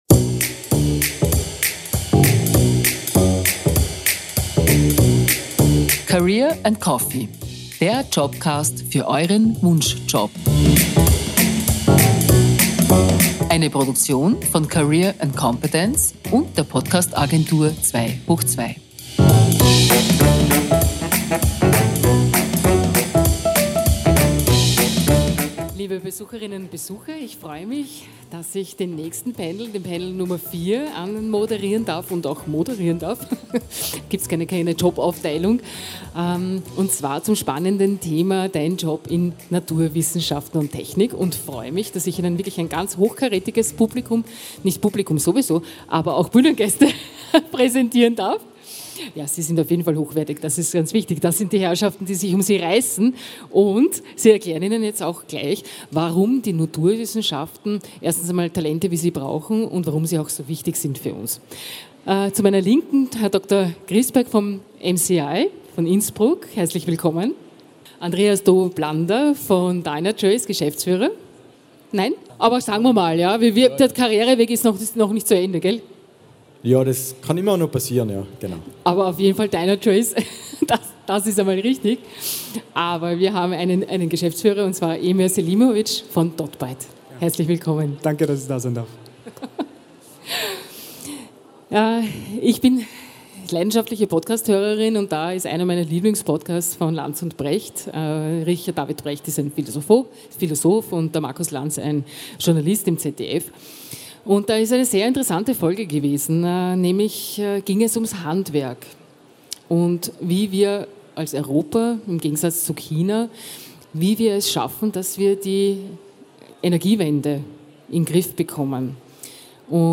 Die vielfältigen Möglichkeiten, um eine Karriere in den Bereichen Naturwissenschaft und Technologie zu starten. Expert:innen diskutieren über die Herausforderungen und Chancen in diesen Branchen und geben wertvolle Einblicke in mögliche Karrierewege.
Livemitschnitt von der career & competence 2023 in Innsbruck am 26. April 2023.